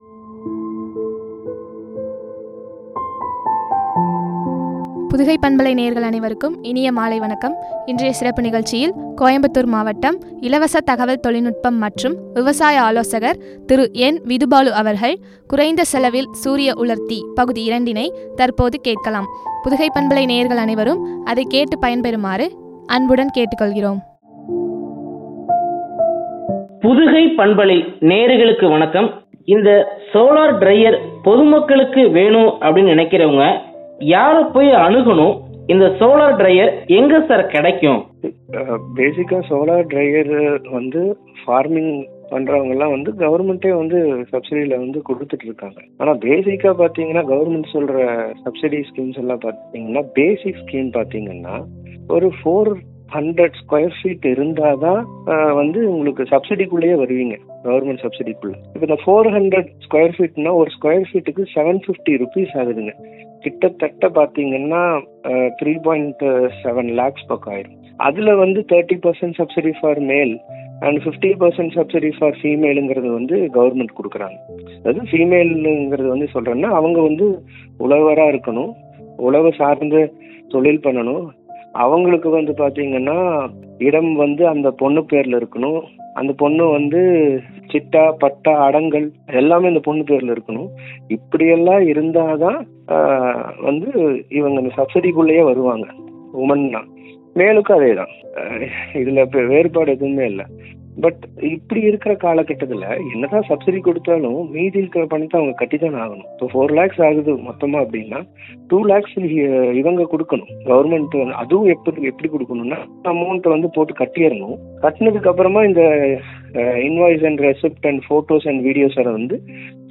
பகுதி -02” பற்றிய உரையாடல்